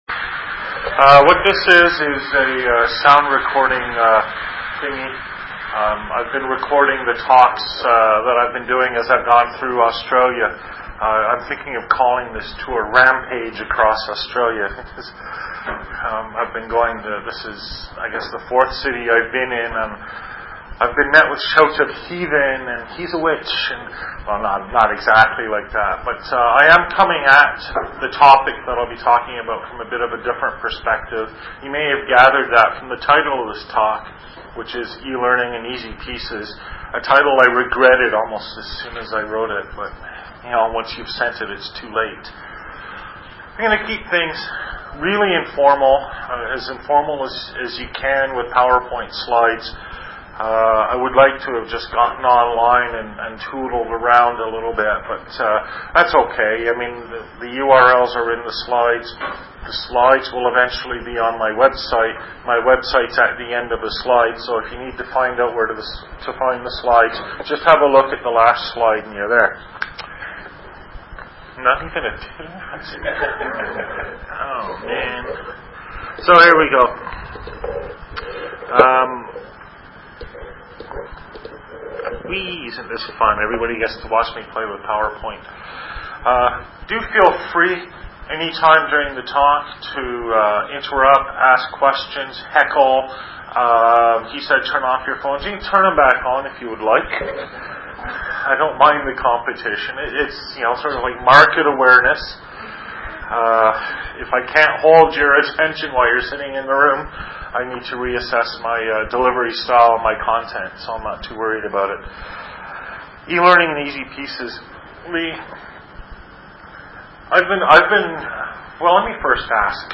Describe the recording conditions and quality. Invited Lecture, Training Advisory Council, Australian Flexible Learning Framework, Darwin, NT, Australia, Lecture, Sept 24, 2004.